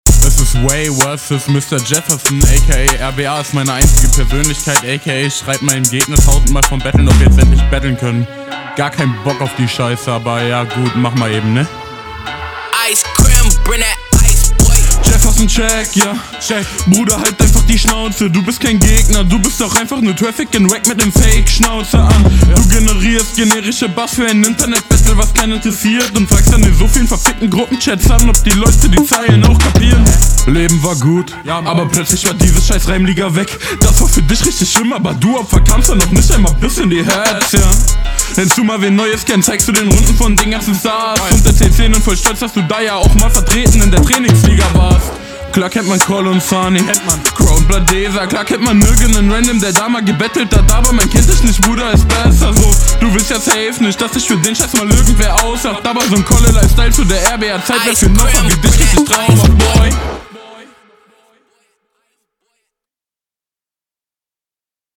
flow endlich mal dope ker. mag ich. kaum off, und inhaltlich stringent ja, hab ich …
Flowlich klingt das stimmig zum Beat und zum Sample.